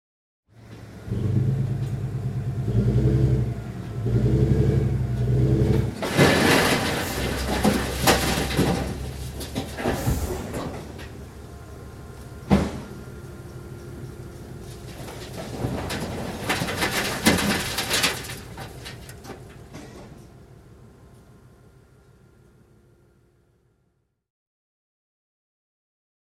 Звуки мусоровоза
Грохот мусора в мусоросборнике под загрузкой мусоровоза